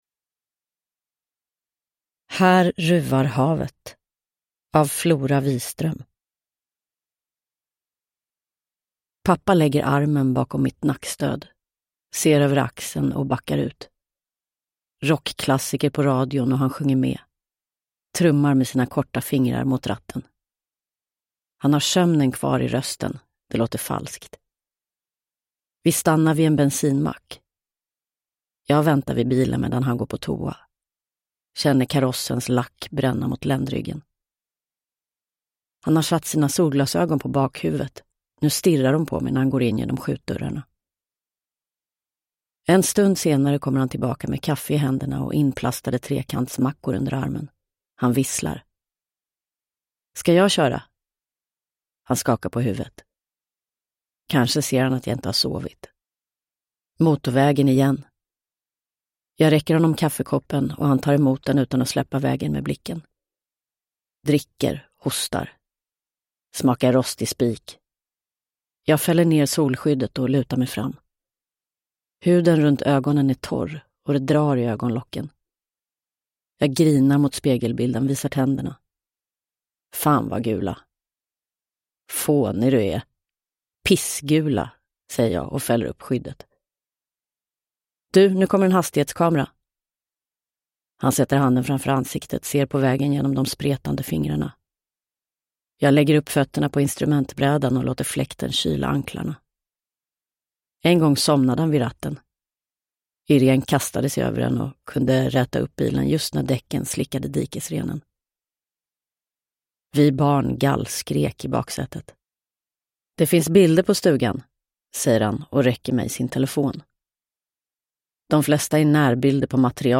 Här ruvar havet – Ljudbok – Laddas ner
Uppläsare: Rebecka Hemse